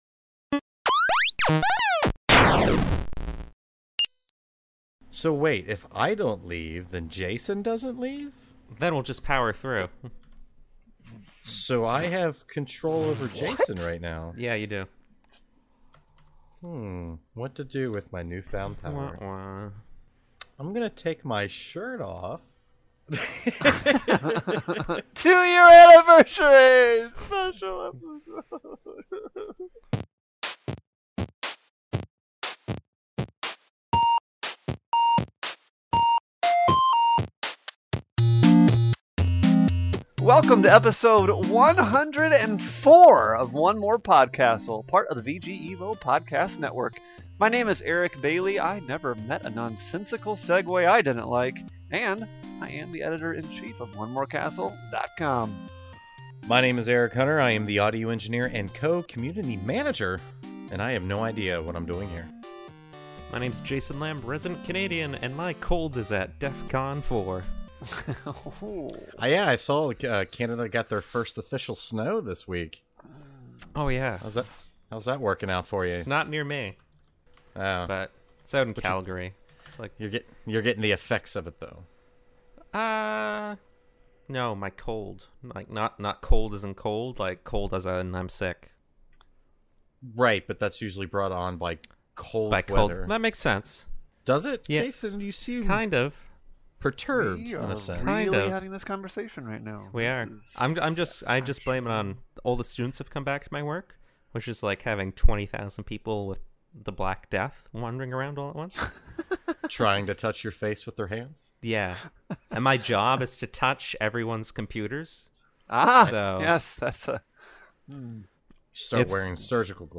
Listener email discussion question